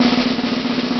Quick Drum Roll Sound Effect Free Download
Quick Drum Roll